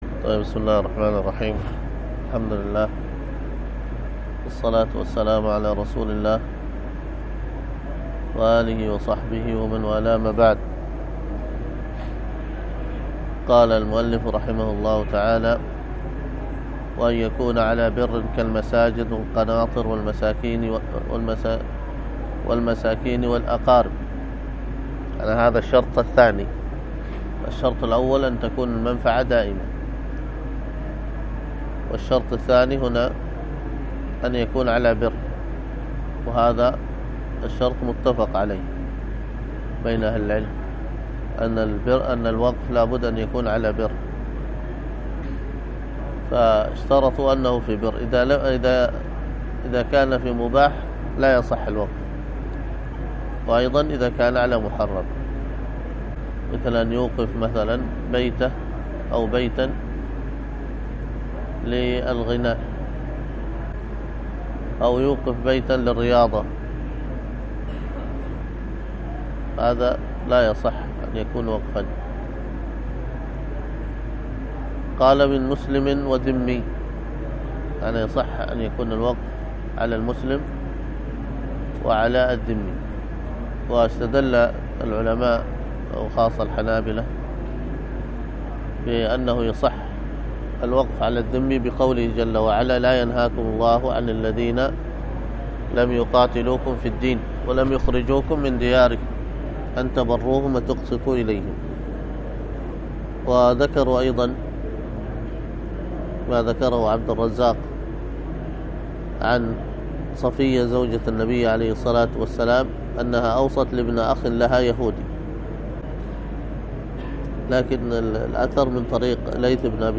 الدروس الفقه وأصوله